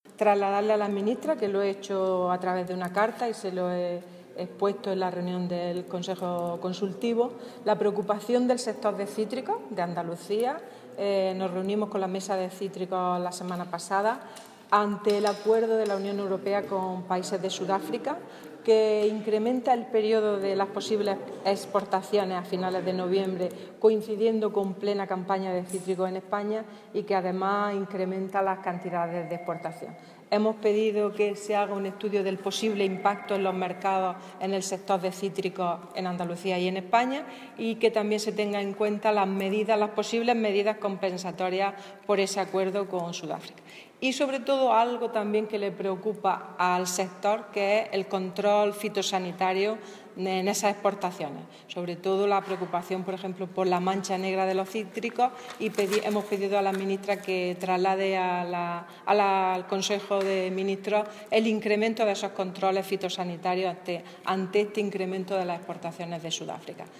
Declaraciones de Carmen Ortiz sobre el convenio de la Unión Europea y países sudafricanos